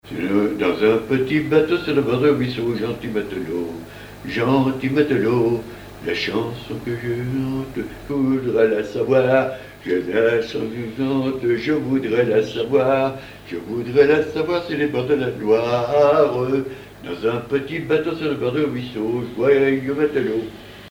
Genre laisse
Chansons et commentaires
Pièce musicale inédite